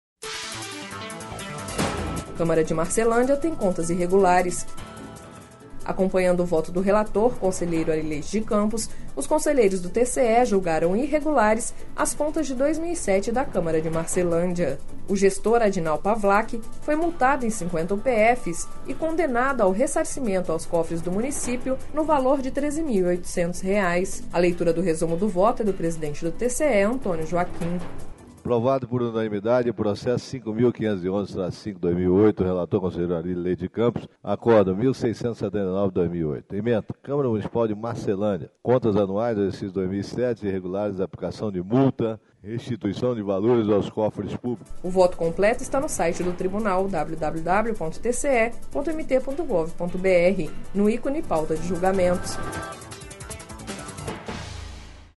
Acompanhando o voto do relator, conselheiro Ary Leite de Campos, os conselheiros do TCE-MT julgaram irregulares as contas de 2007 da Câmara de Marcelândia./ O gestor Adinal Pavlak, foi multado em 50 UPFs/MT e condenado ao ressarcimento aos cofres do município no valor de R$ 13.800,00./ A leitura do resumo do voto é do presidente do TCE-MT, Antonio Joaquim.//
Sonora: Antonio Joaquim - conselheiro presidente do TCE-MT